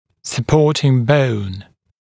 [sə’pɔːtɪŋ bəun][сэ’по:тин боун]поддерживающая кость, окружающая кость